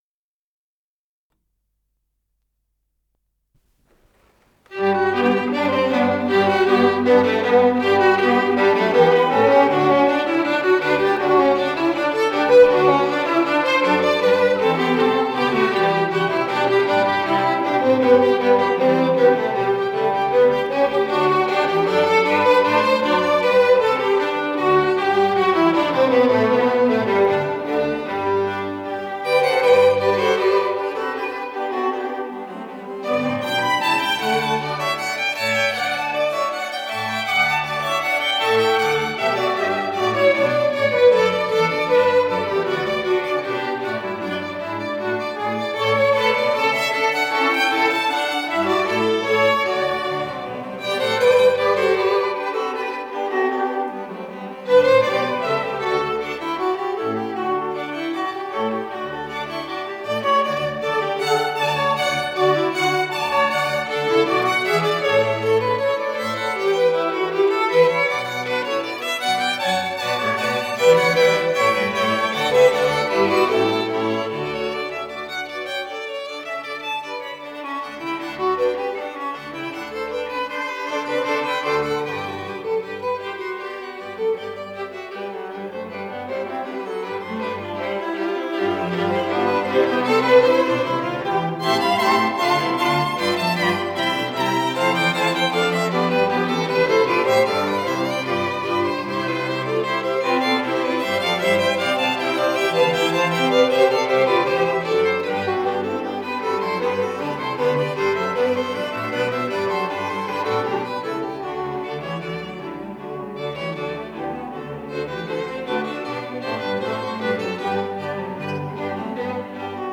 Запись из концертного зала им. Гнесиных